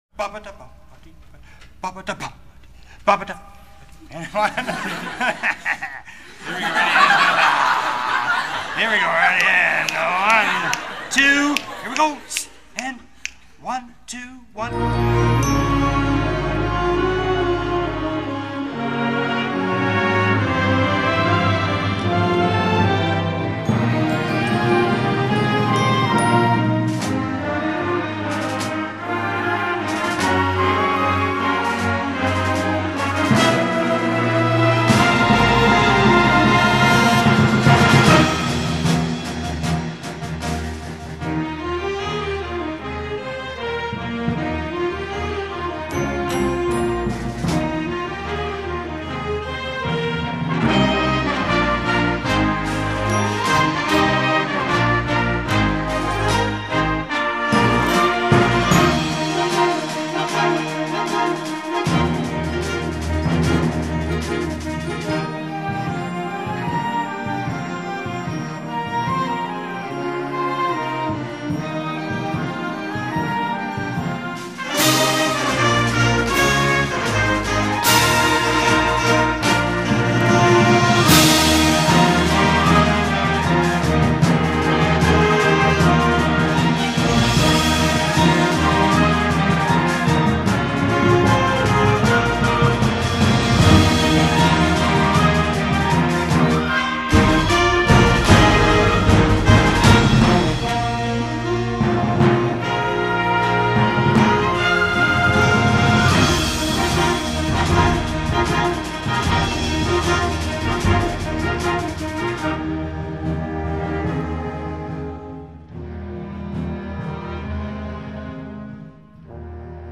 S C H O O L   C O N C E R T   B A N D S